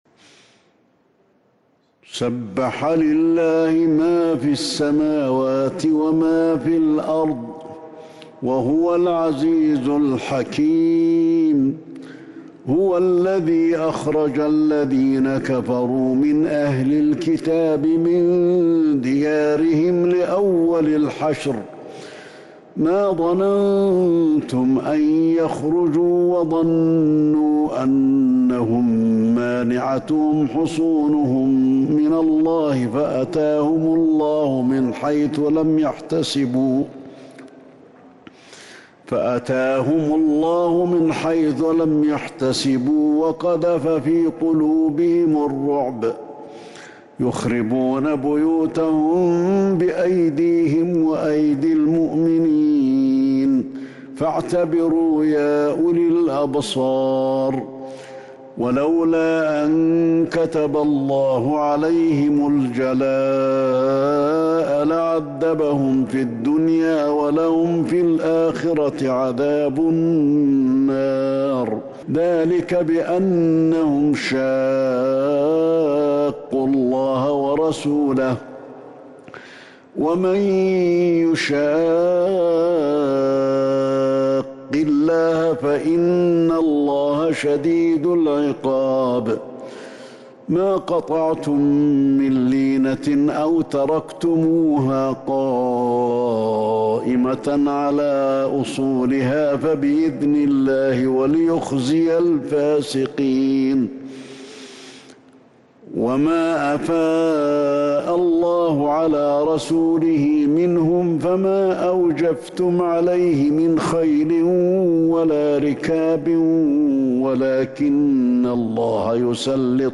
سورة الحشر Surah Al-Hashr من تراويح المسجد النبوي 1442هـ > مصحف تراويح الحرم النبوي عام 1442هـ > المصحف - تلاوات الحرمين